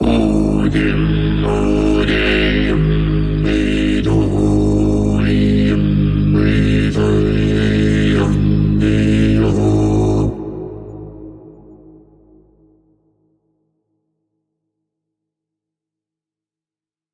Звук шамана, произносящего заклинание (игровой эффект)